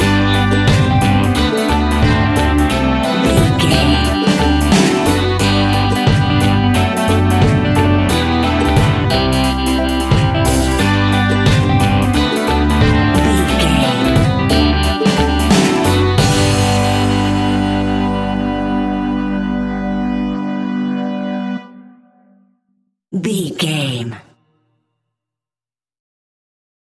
Hot summer reggae music from Barbados!
Uplifting
Ionian/Major
laid back
chilled
off beat
drums
skank guitar
hammond organ
percussion
horns